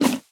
Minecraft Version Minecraft Version snapshot Latest Release | Latest Snapshot snapshot / assets / minecraft / sounds / mob / llama / eat1.ogg Compare With Compare With Latest Release | Latest Snapshot
eat1.ogg